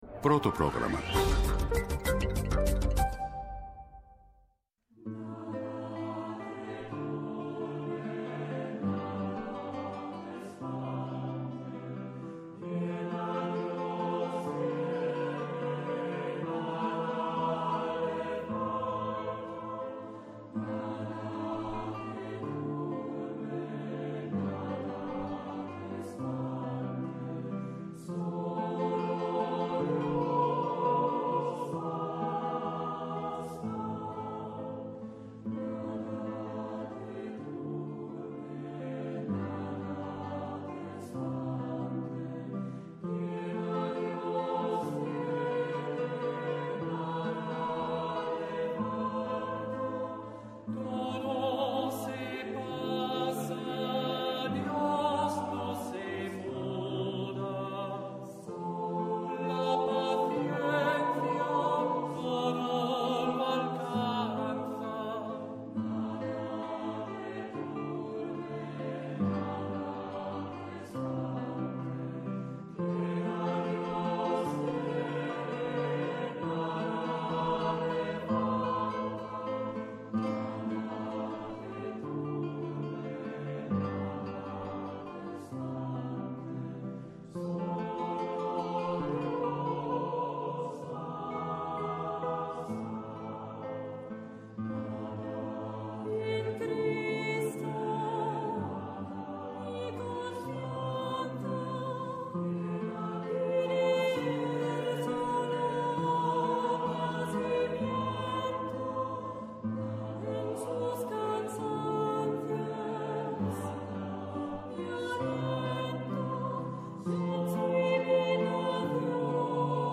Σήμερα καλεσμένοι: